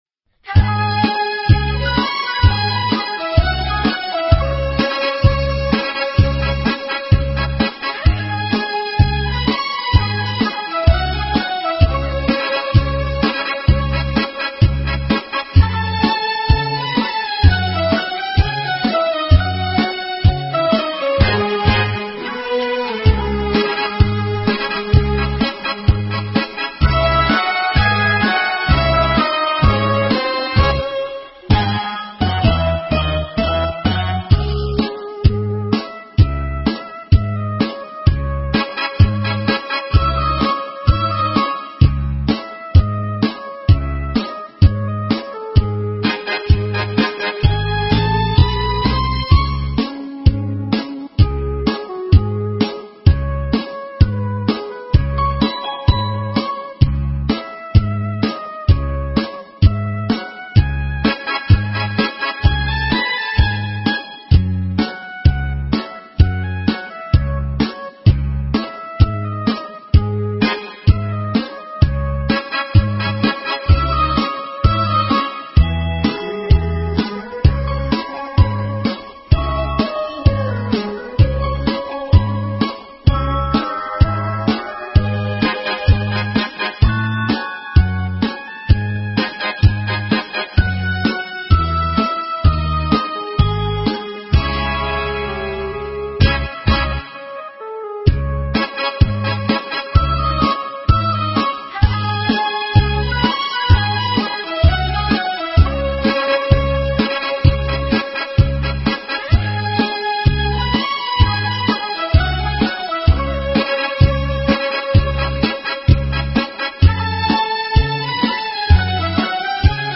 반주곡을 미리들어보시려면 첨부파일 위 링크를 클릭하세요.